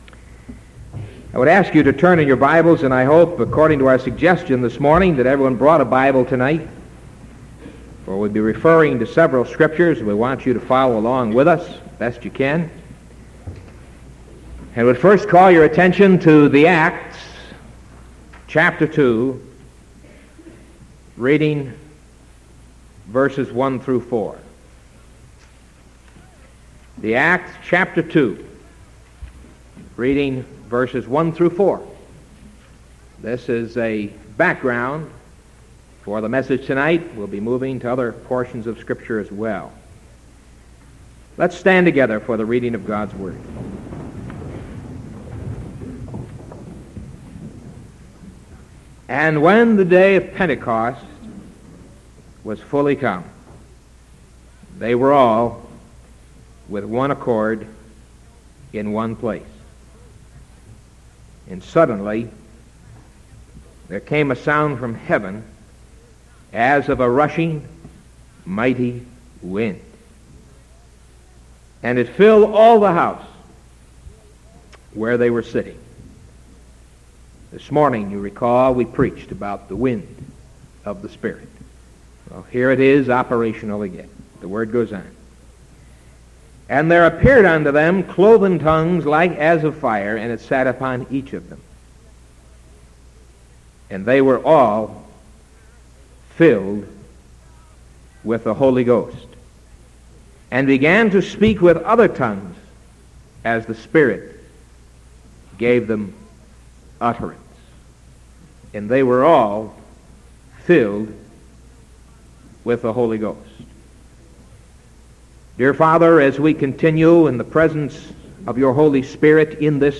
Sermon January 20th 1974 PM